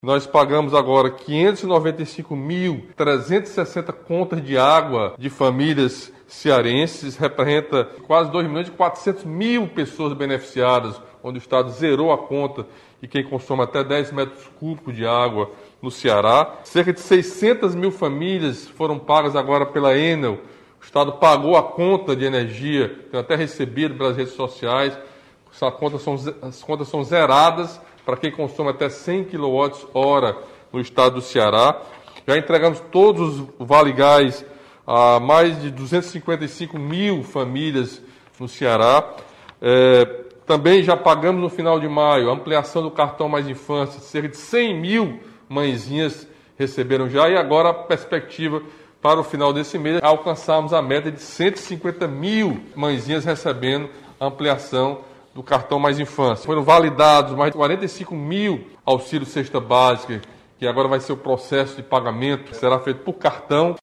O governador também listou ações desenvolvidas para minimizar os efeitos da crise causada pela pandemia, principalmente para as famílias em situação de vulnerabilidade social.